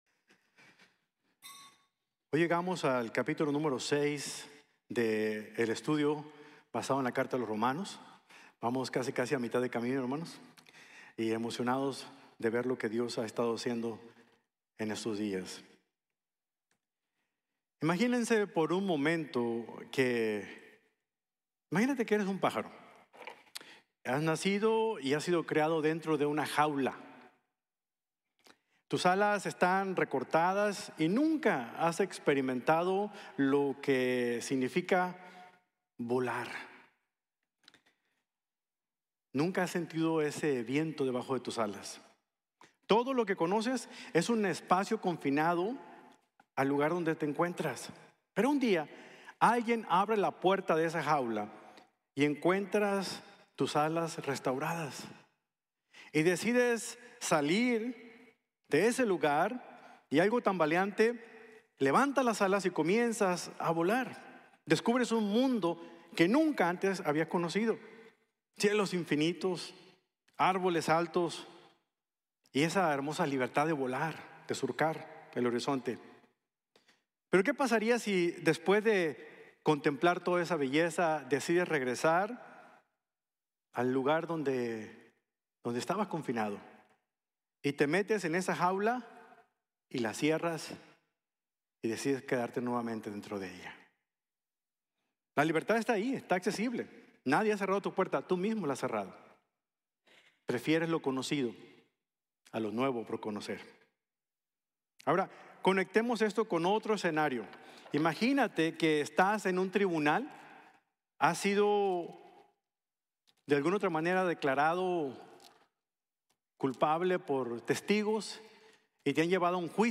Libre Para Servir | Sermon | Grace Bible Church